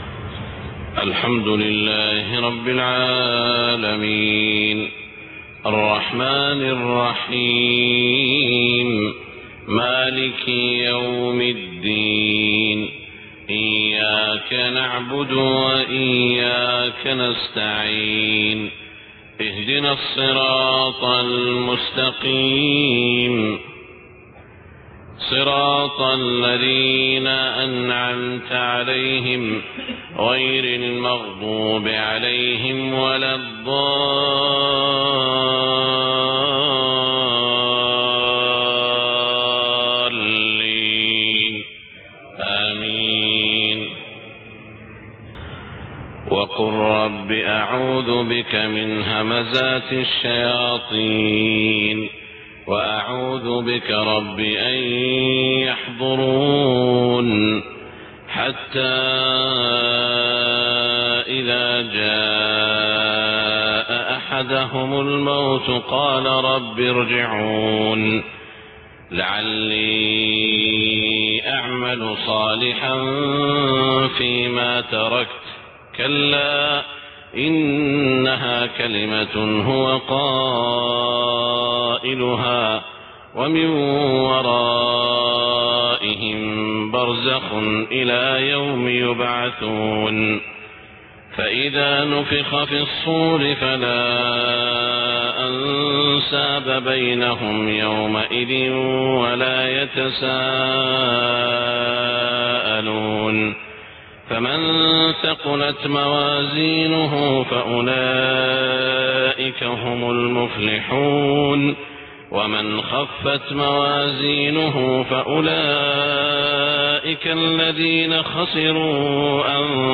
صلاة الفجر 26 ربيع الأول 1430هـ خواتيم سورة المؤمنون > 1430 🕋 > الفروض - تلاوات الحرمين